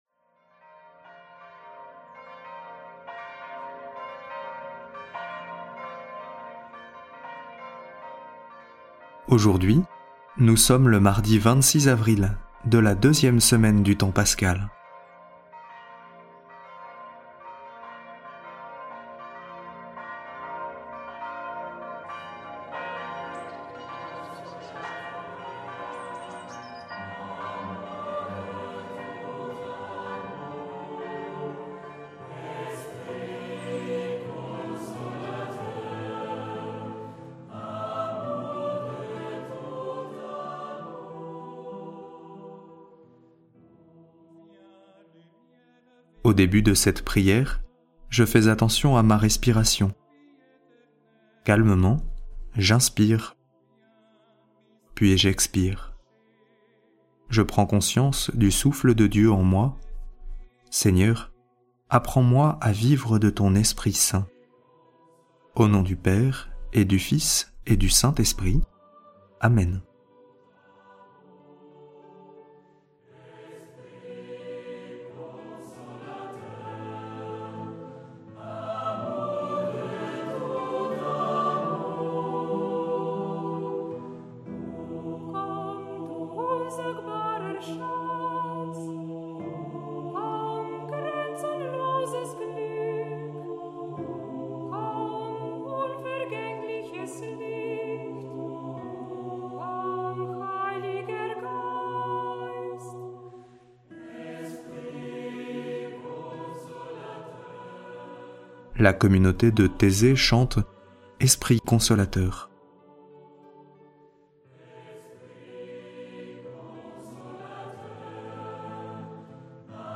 Prière audio avec l'évangile du jour - Prie en Chemin